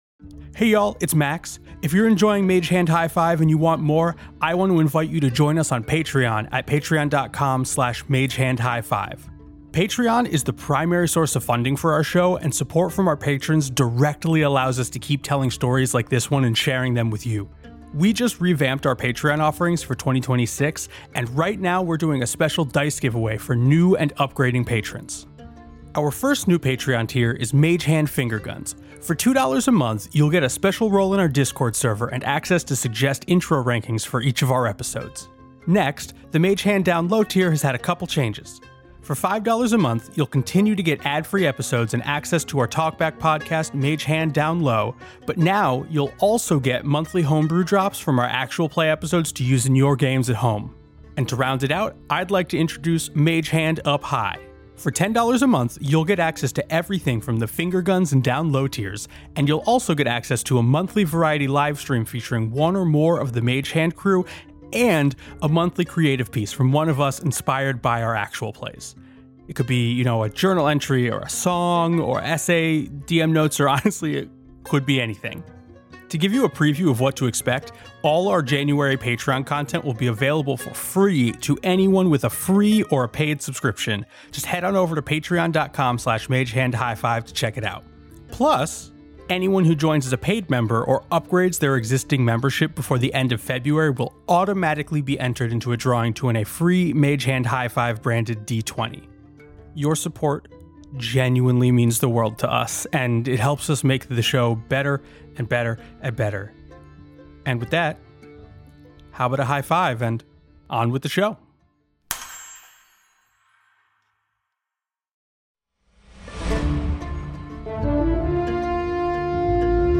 actual play podcast